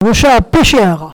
locutions vernaculaires